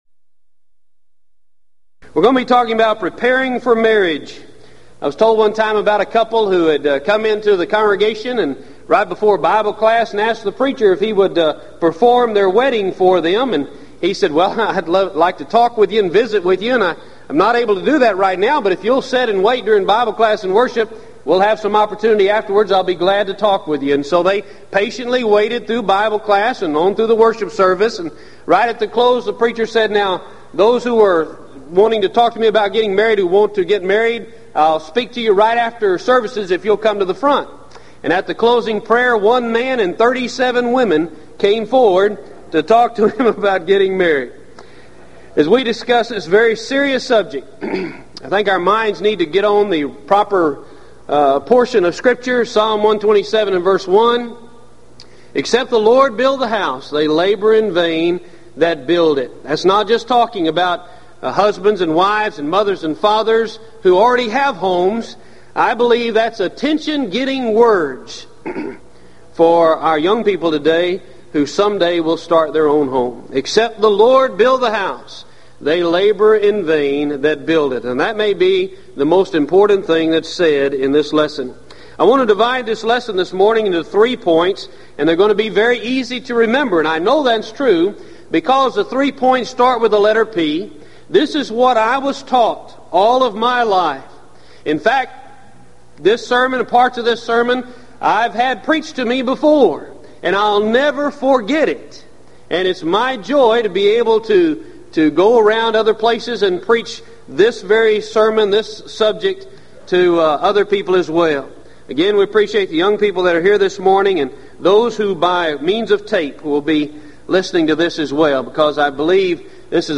Event: 1996 Gulf Coast Lectures
lecture